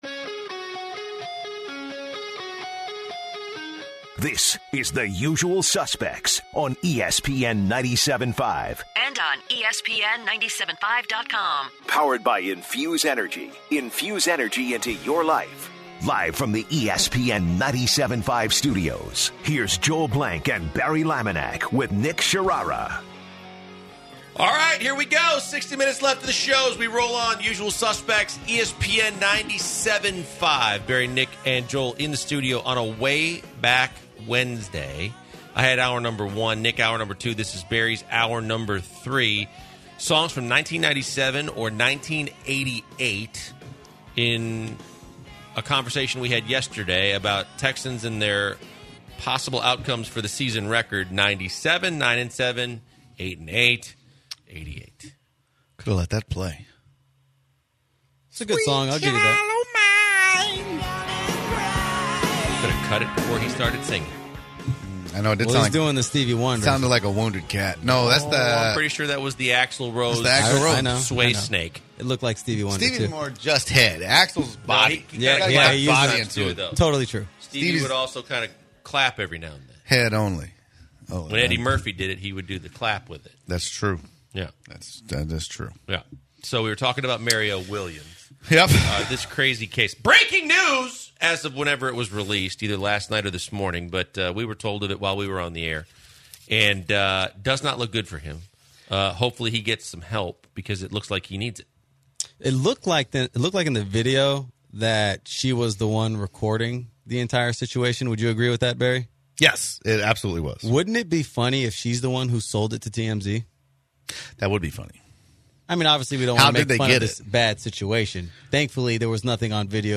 Then the guys talk about the arrest of Luis Castillo and Octavio Dotel and all the crazy facts coming out about it. Next, they get a caller wanting to talk about Aaron Rodgers and if he is still at the elite level he was 3 years ago.